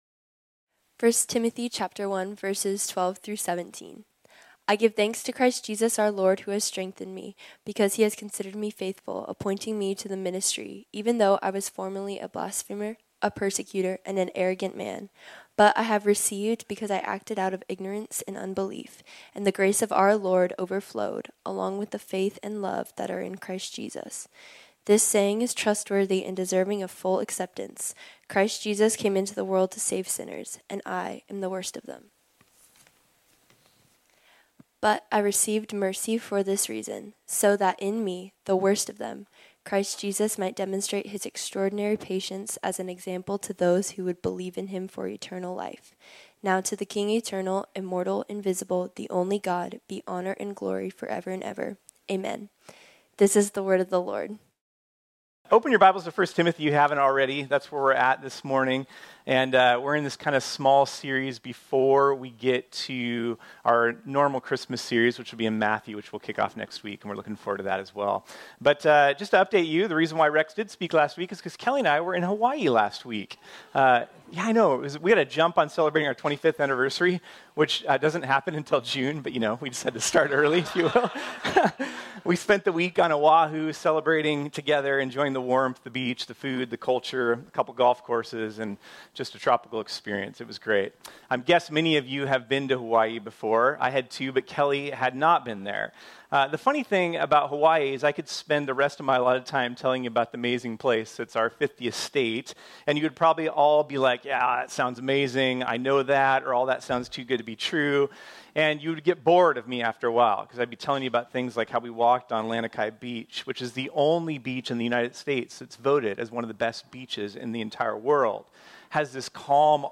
This sermon was originally preached on Sunday, December 10, 2023.